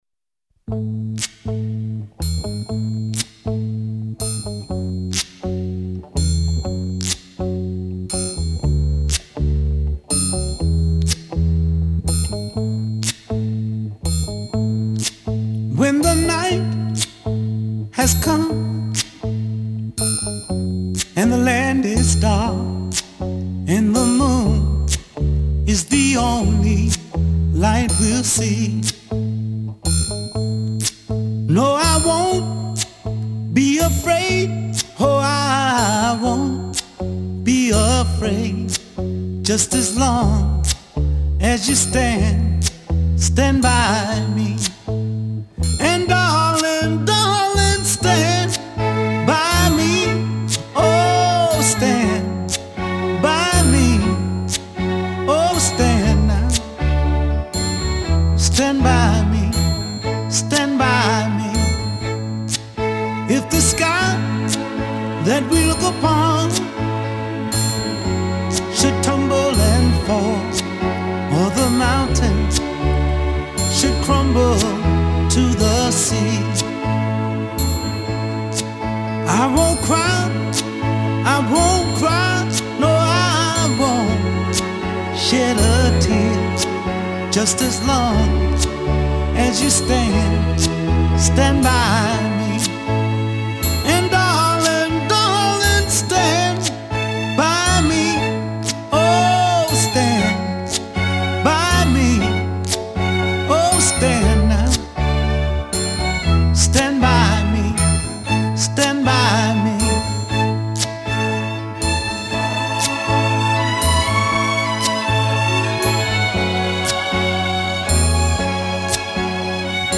cântăreţul american de muzică soul